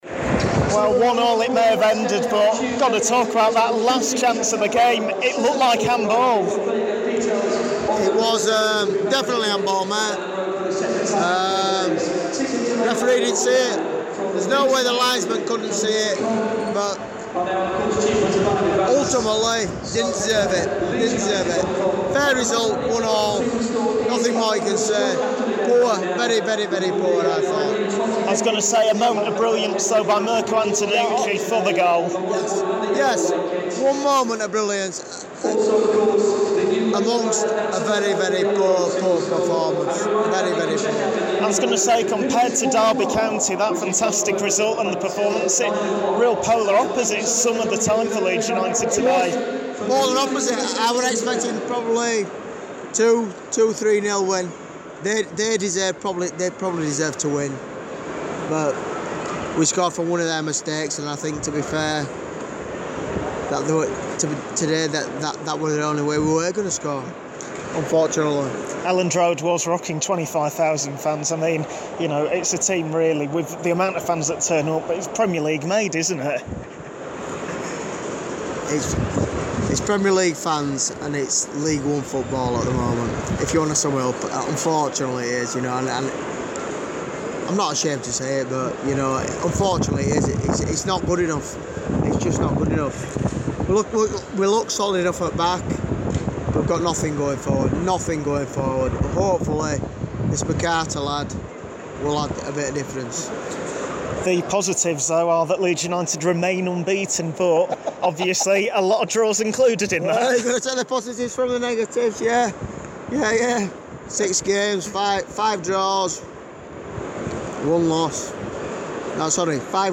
Leeds United fans react to 1-1 draw with Brentford